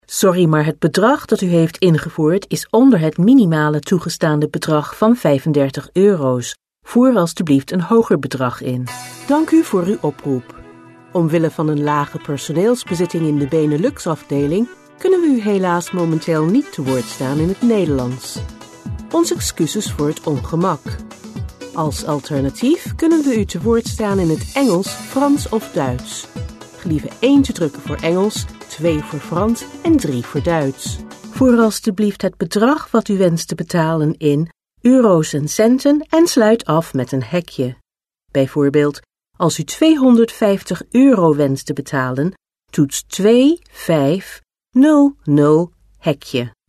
Female / 40s, 50s / Dutch
Showreel